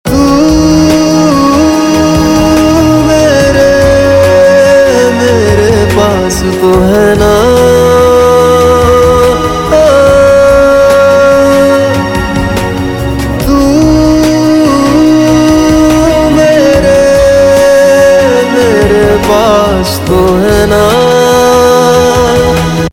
Bollywood - Hindi